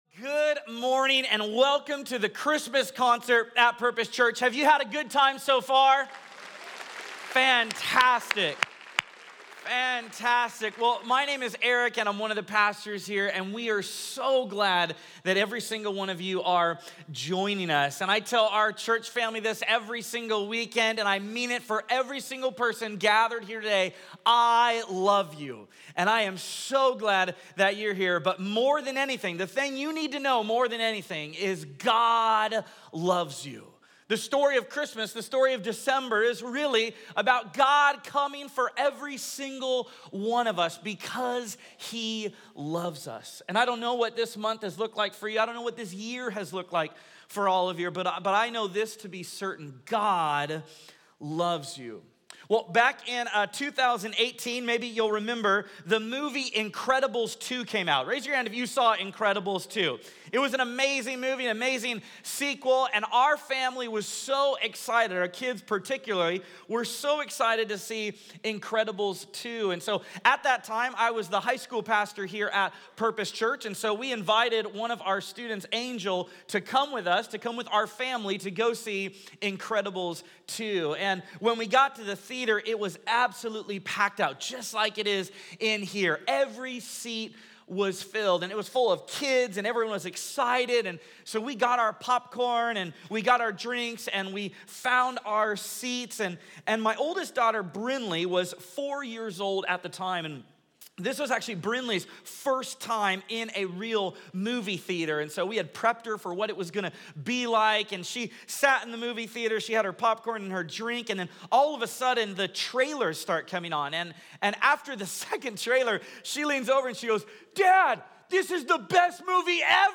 Star of Wonder | A Christmas Concert 2024